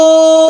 m_chest.wav